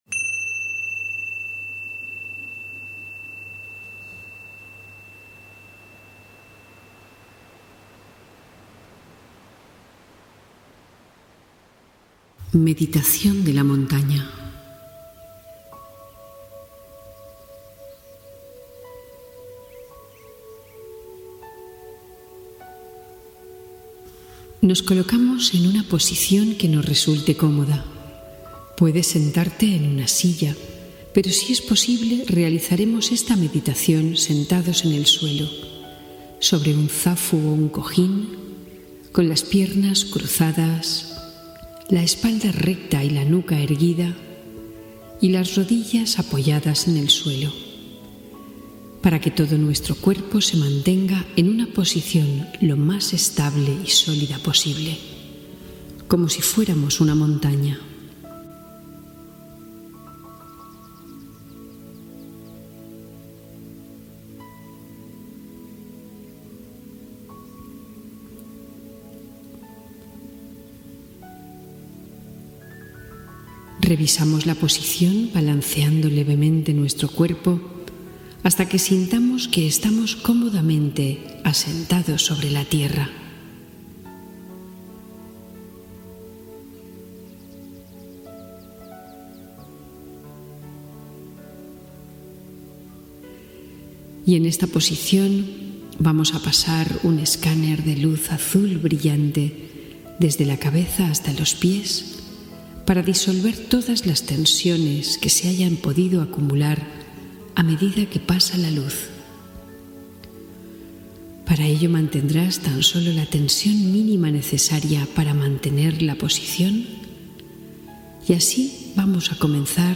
Meditación guiada de la montaña: paz interior y estabilidad emocional